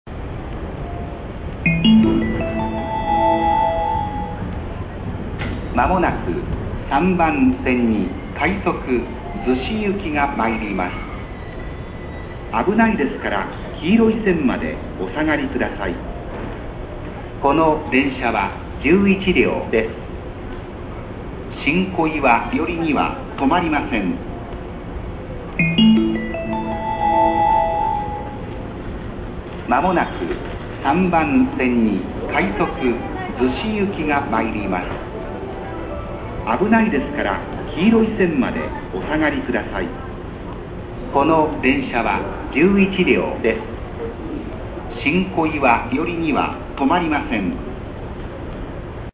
「新小岩/寄りには」は